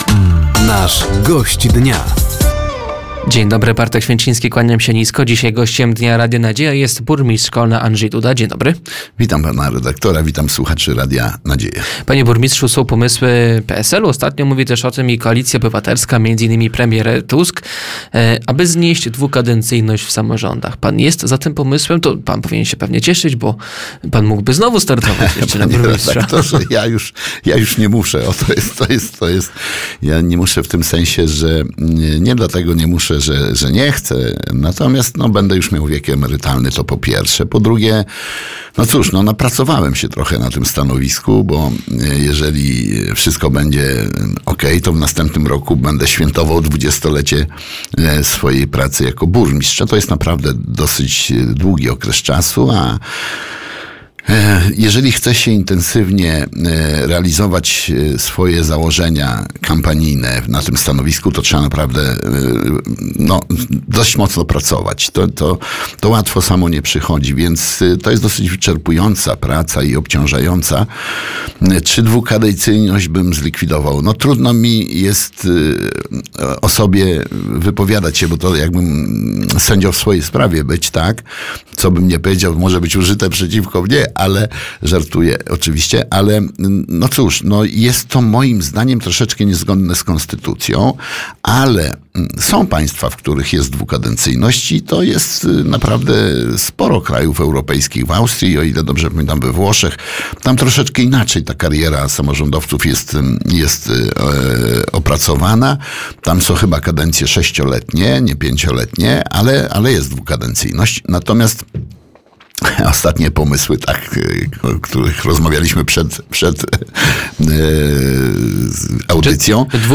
Dwukadencyjność samorządowców, przystąpienie Miasta Kolno do Klastra Energii Grabowo czy plany budowy zachodniej obwodnicy miasta. Między innymi o tych tematach mówił nasz poniedziałkowy (27.10) Gość Dnia, którym był burmistrz Kolna Andrzej Duda.